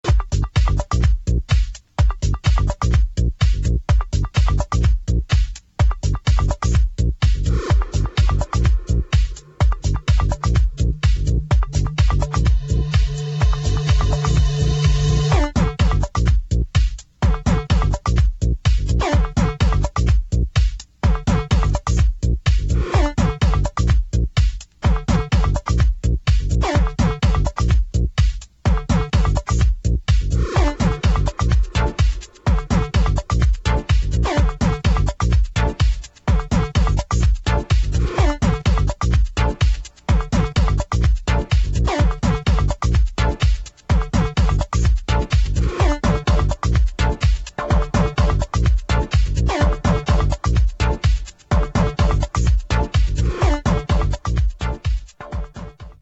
[ TECH HOUSE / PROGRESSIVE HOUSE ]